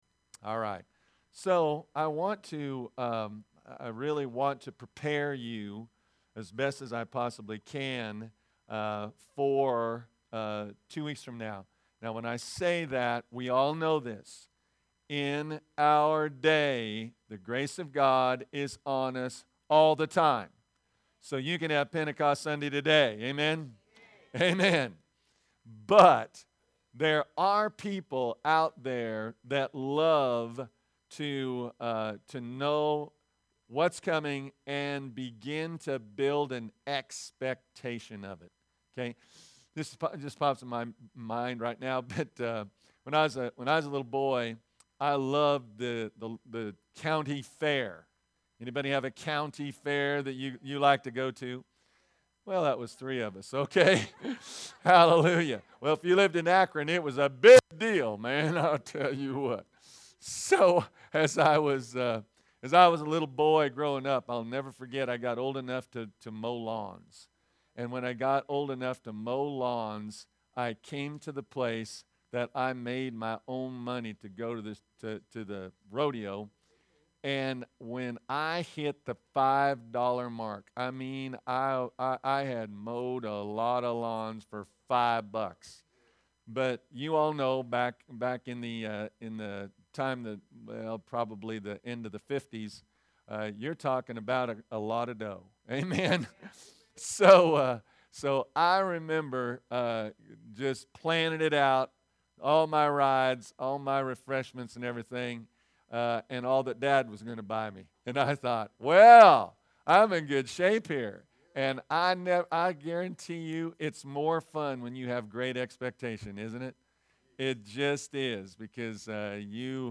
Sermons | Victory Christian Fellowship